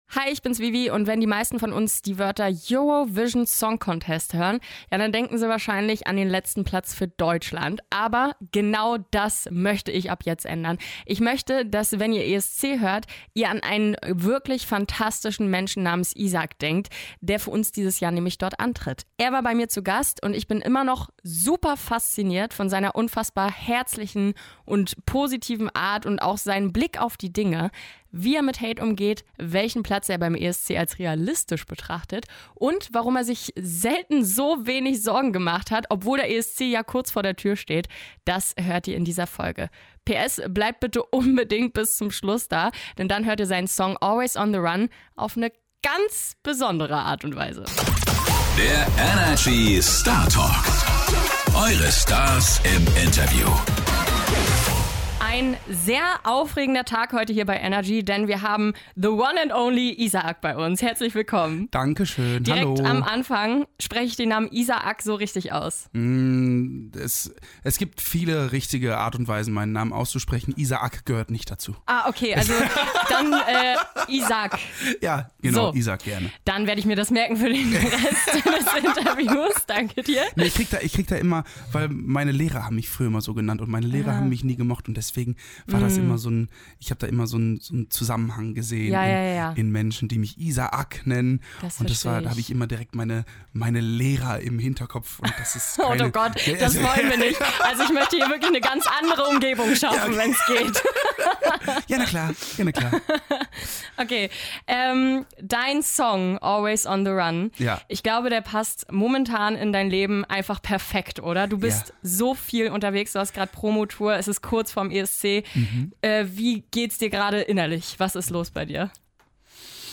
In dieser neuen Folge sprechen wir mit unserem ESC-Kandidaten: Isaak!